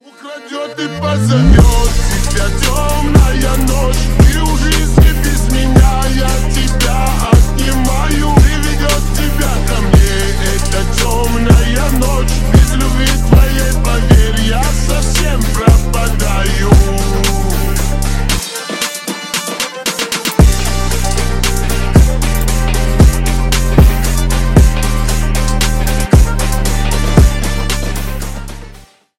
• Качество: 128, Stereo
ремиксы
фонк